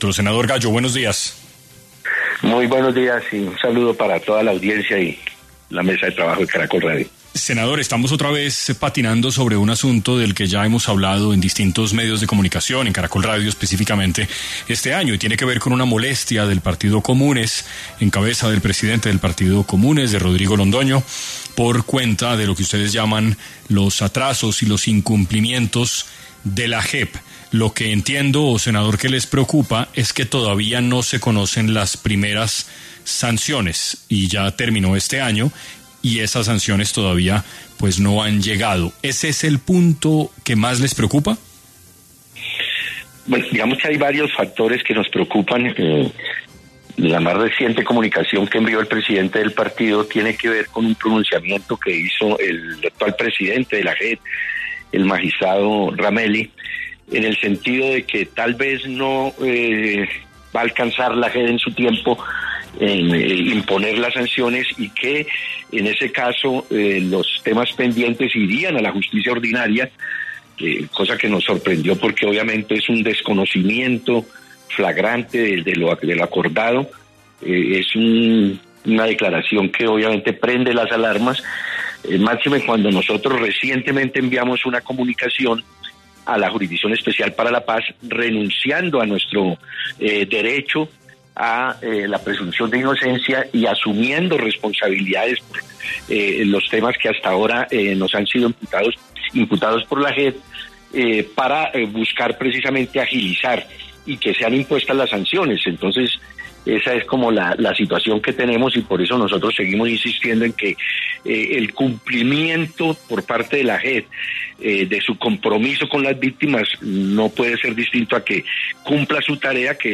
Julián Gallo, senador del Partido Comunes, habló en 6AM sobre la molestia del Partido, por los atrasos e incumplimientos de la JEP